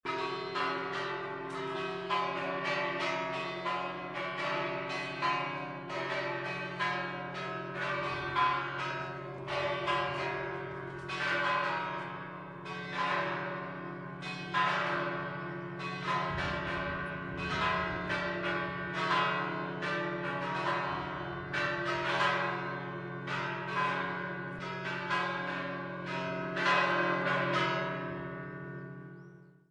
锡耶纳大教堂（Duomo di Siena）的教堂钟声
描述：中央寺院二锡耶纳（锡耶纳大教堂）的教堂钟（在锡耶纳）（意大利）。用Sony PCM50录制
Tag: 钟声 大教堂 教堂-bells 锡耶纳 大教堂二锡耶纳 中世纪 锡耶纳 锡耶纳大教堂 教堂 现场录制 大教堂的钟声